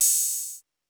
Perc (4).WAV